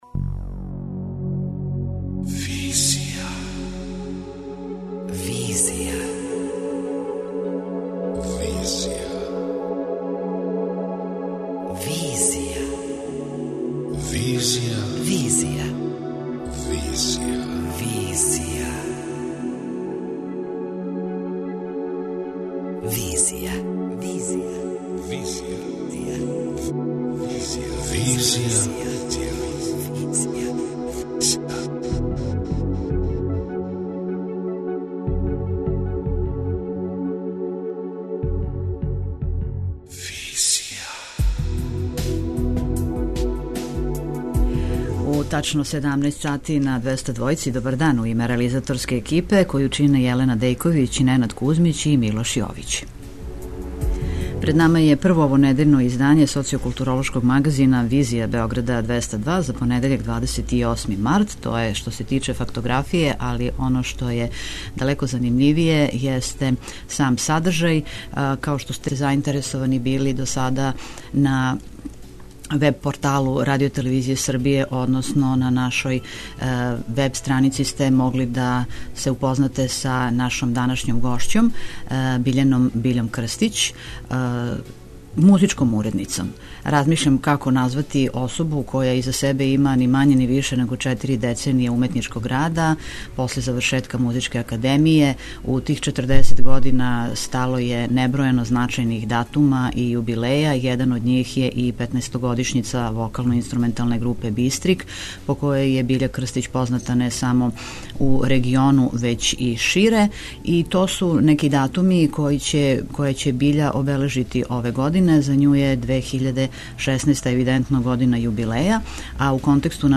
преузми : 28.37 MB Визија Autor: Београд 202 Социо-културолошки магазин, који прати савремене друштвене феномене.